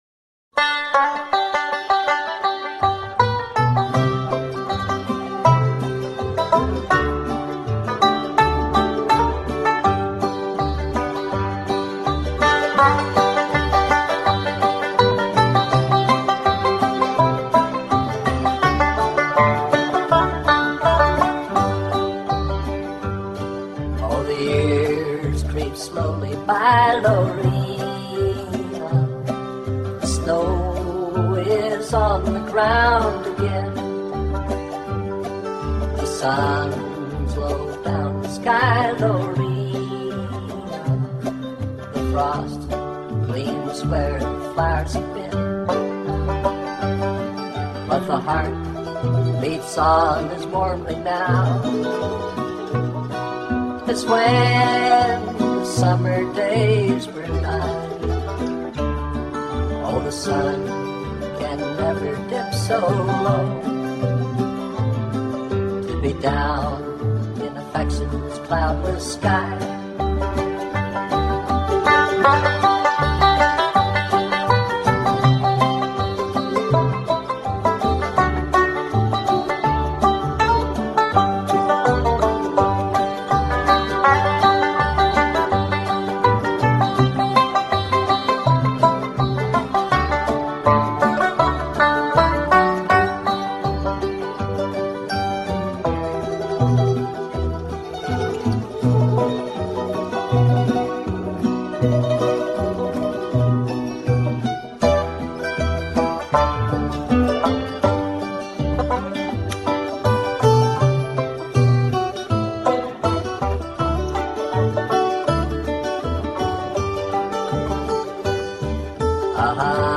Another member-generated podcast of great banjo tunes.
old tie-hacker tune